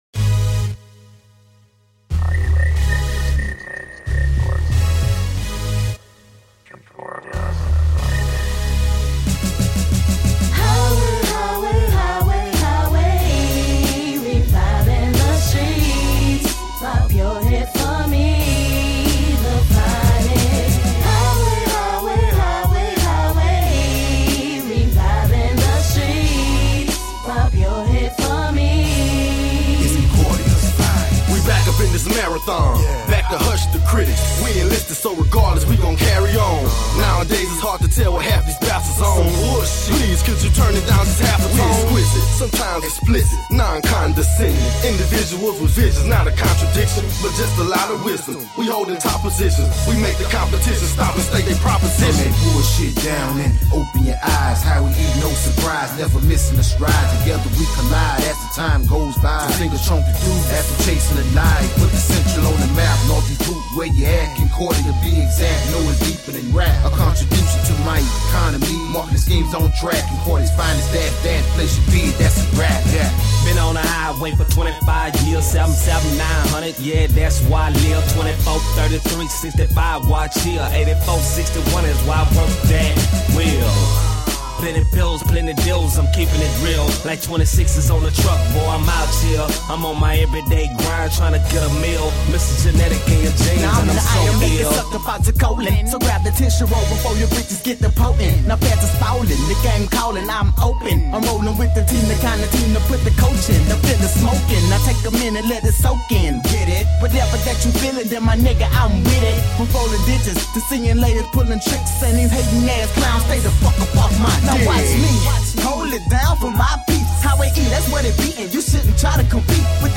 Excellent new school feeling hip-hop.